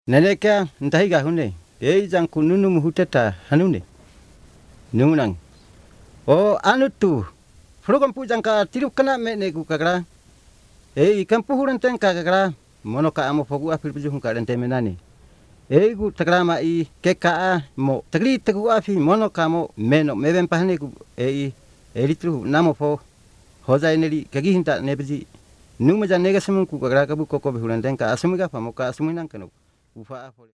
These are recorded by mother-tongue speakers